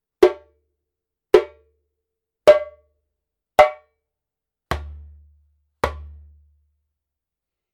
Djembe shell made in Mali KANGABA workshop
Wood :Gueni (Balafon Wood)
皮厚み中庸。瑞々しく張りがあるクリアな音色、軽量ボディがよく響き、心地よく演奏出来るジャンベです。
ジャンベの音